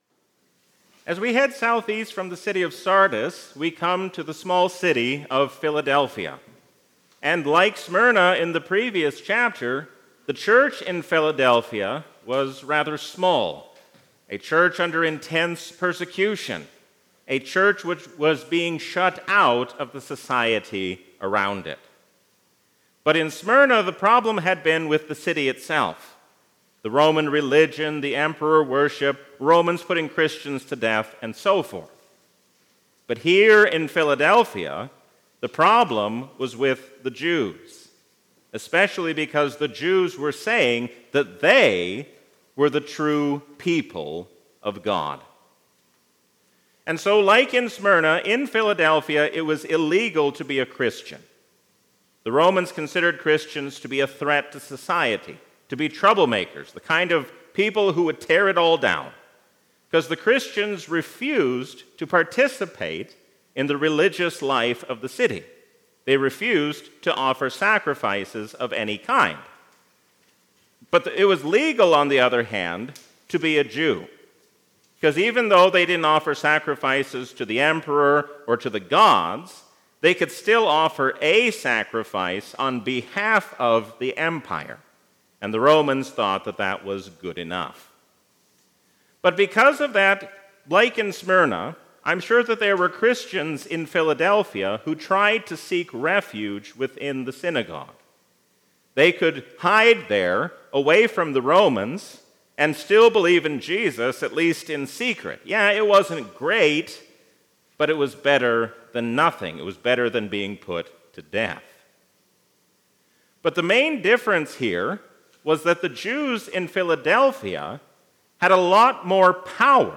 A sermon from the season "Trinity 2023." We are called to faithfully carry out the work given to us while we wait for the Last Day.